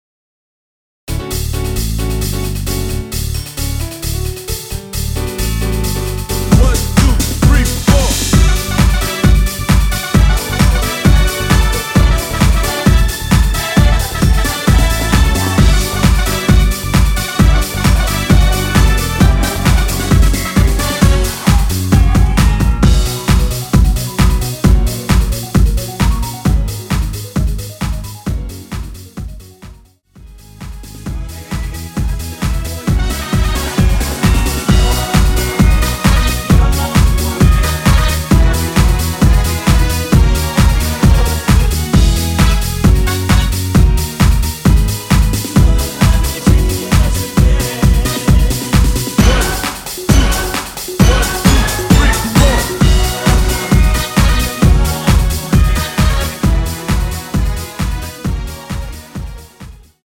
코러스 MR입니다.
원키에서(-1)내린 코러스 포함된 MR입니다.
◈ 곡명 옆 (-1)은 반음 내림, (+1)은 반음 올림 입니다.
앞부분30초, 뒷부분30초씩 편집해서 올려 드리고 있습니다.